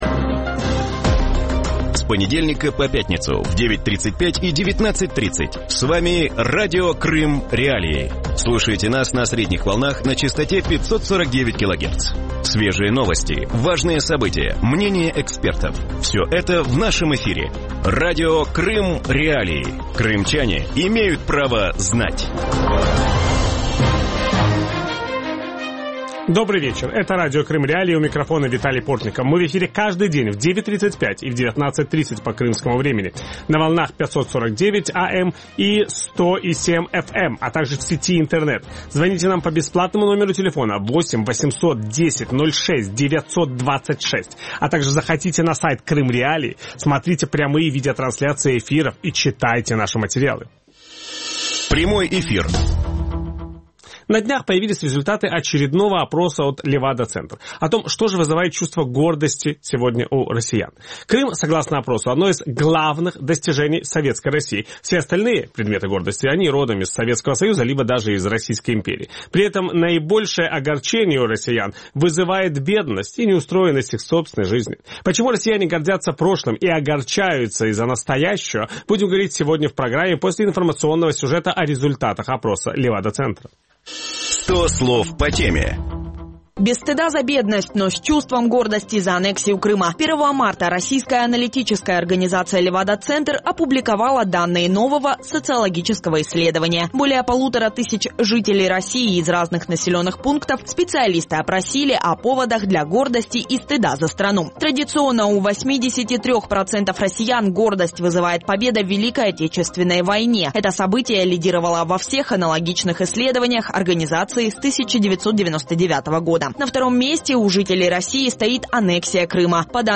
В вечернем эфире Радио Крым.Реалии говорят о результатах социологического опроса «Левада-центра», согласно которым военные победы – главная причина гордости для россиян. Почему прошлое для россиян замещает будущее, как точно социологический барометр отображает ситуацию в нынешних условиях и что может сбавить милитаристский запал?
Ведущий программы – Виталий Портников.